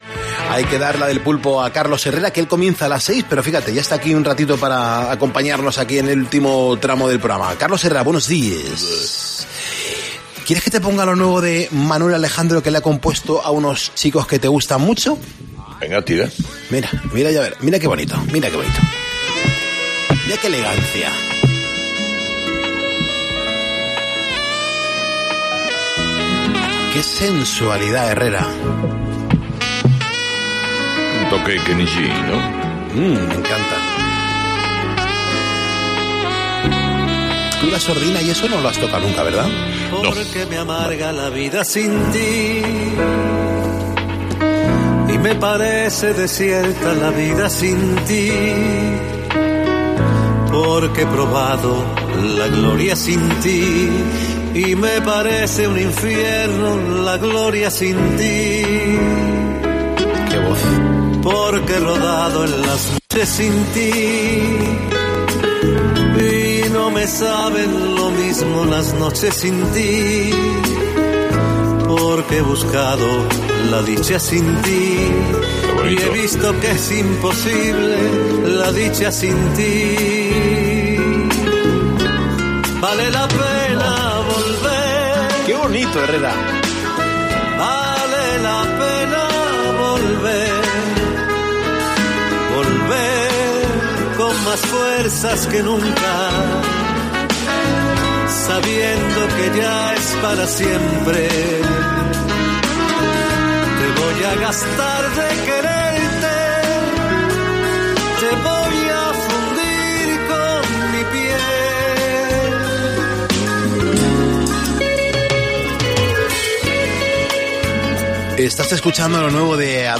Música
Llevan en esto de la música desde el año 1991 y en este 2024, han querido cantar un temazo de Manuel Alejandro, titulado “Vale la pena volver” que ellos cantan con una enorme sensibilidad. Una balada